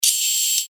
Gemafreie Sounds: High Frequency
mf_SE-3238-hi_freq_hf_servo.mp3